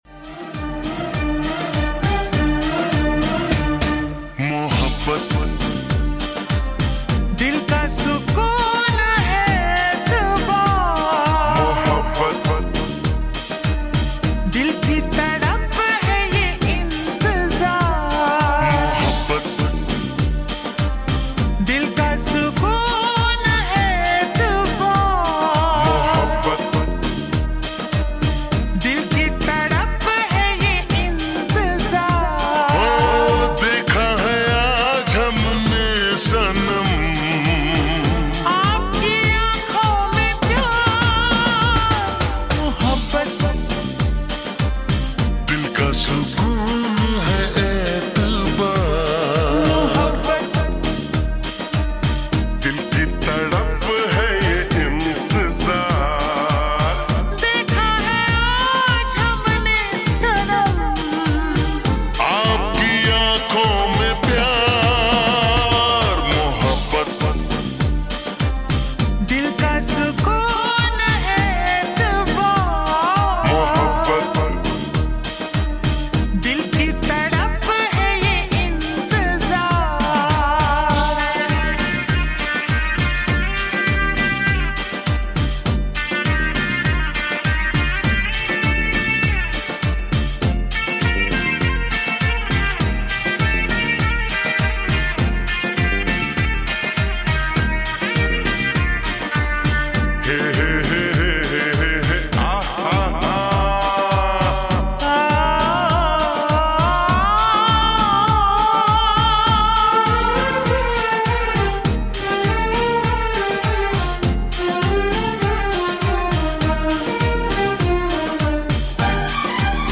slowed reverb
lo-fi mashup songs